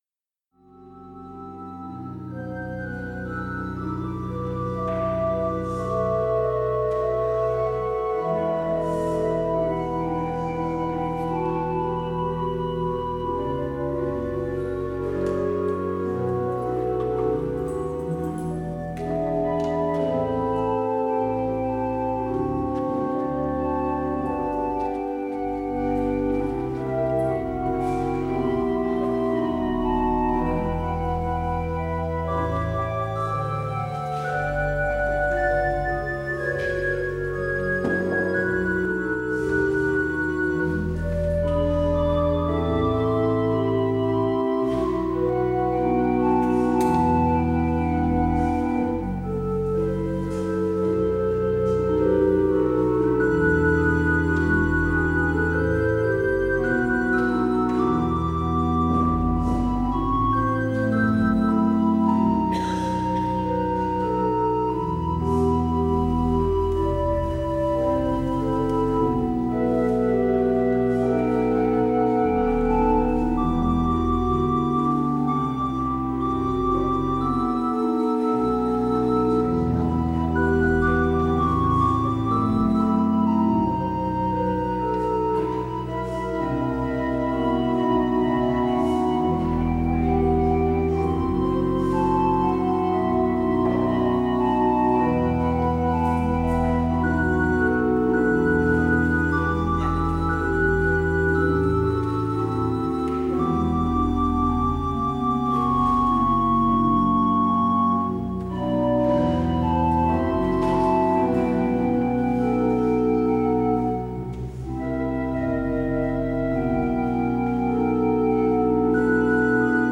 Beluister deze kerkdienst hier: Alle-Dag-Kerk 8 oktober 2025 Alle-Dag-Kerk https